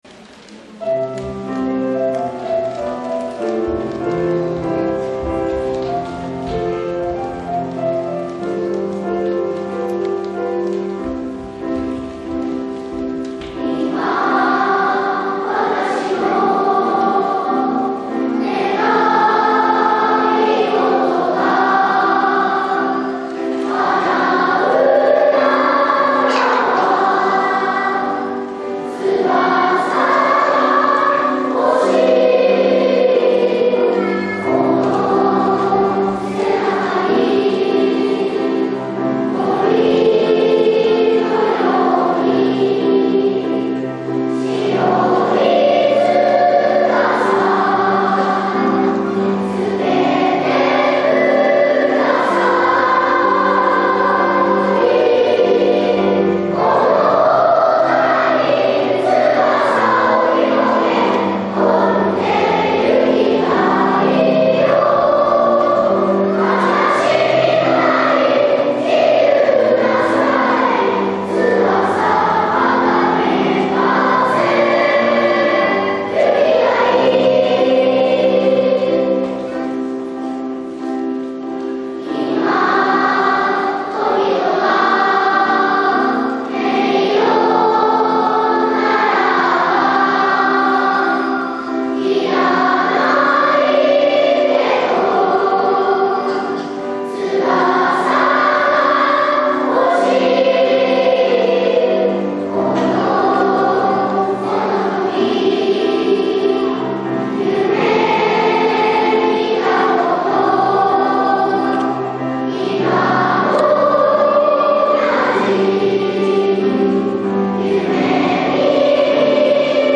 開演前には、講堂が大空の子どもたち、地域・サポーター・ゲストのみなさんでいっぱいになりました。
翼をください」会場２部合唱です。